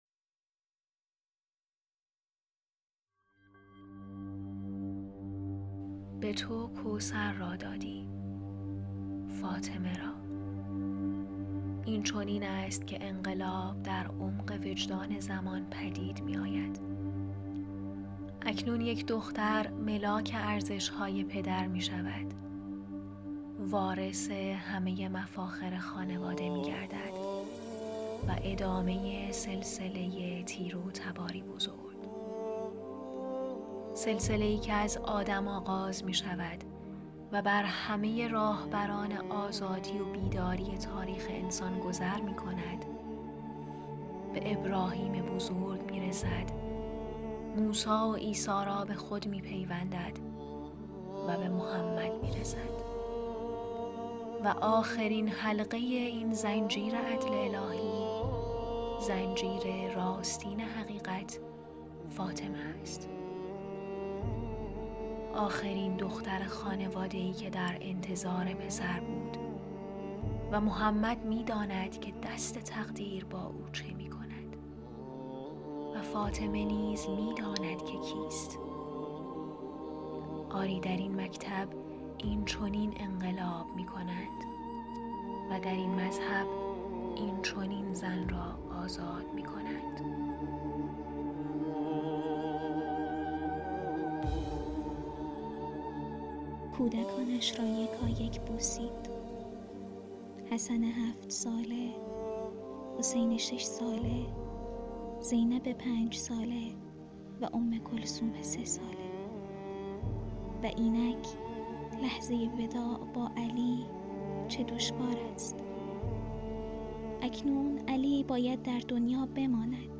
ادیت و صداگذاری